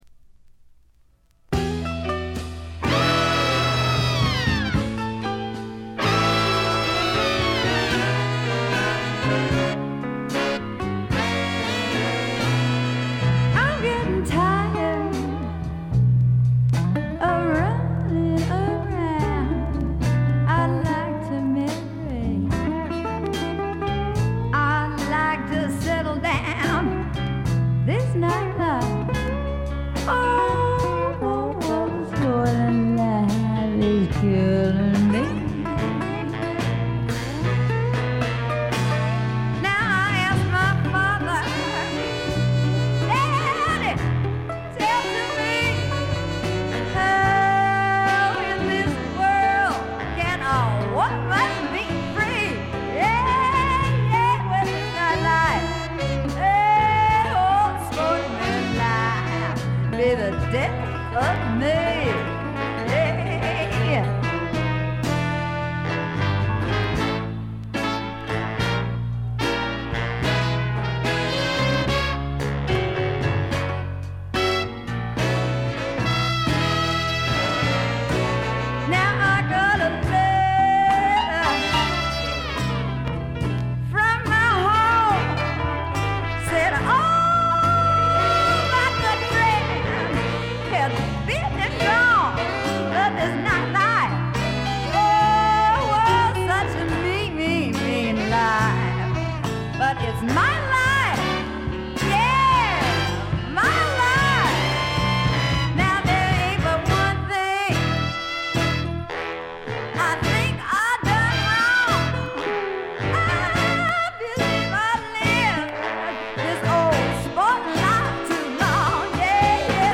散発的なプツ音少し。
スワンプ、R&B、ジャズ、ブルース、サイケのごった煮で
ホーン・セクションを含む大世帯のバンドを狭いライヴ酒場で聴いているような強烈な圧力があります。
試聴曲は現品からの取り込み音源です。
Vocals